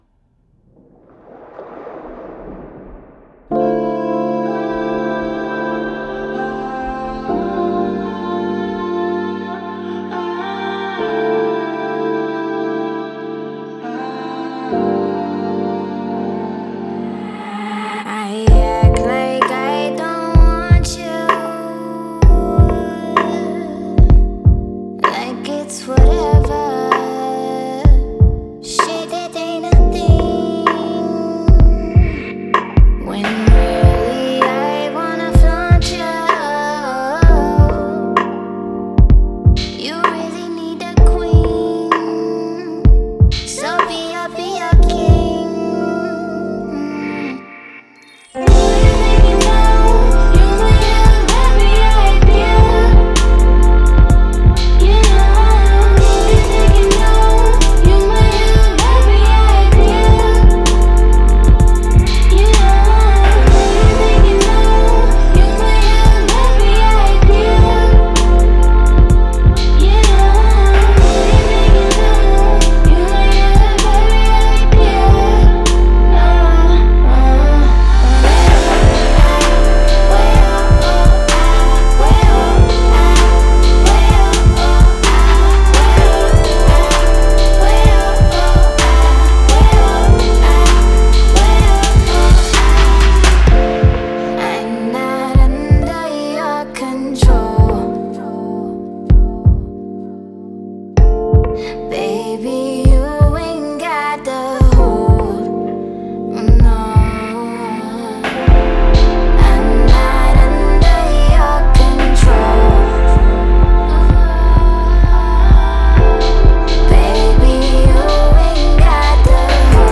سبک هیپ هاپ